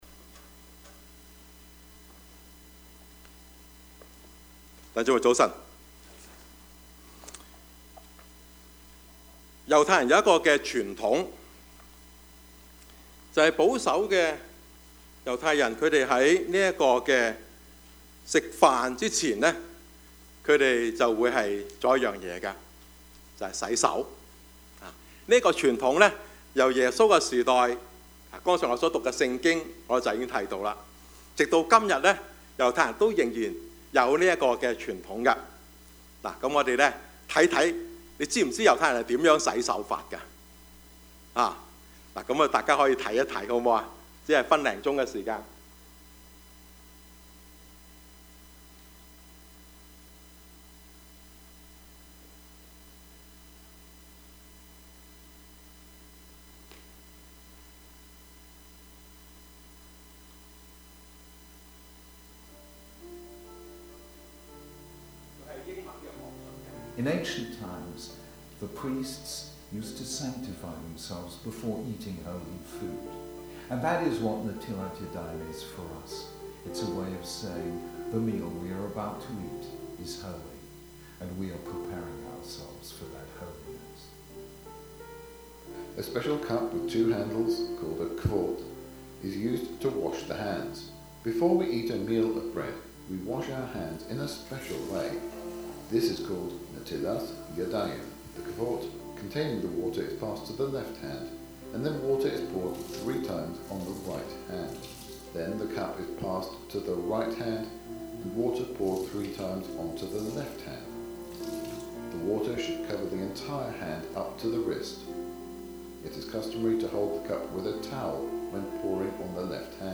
Service Type: 主日崇拜
Topics: 主日證道 « 忙者請聽 權柄何在?